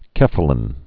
(kĕfə-lĭn)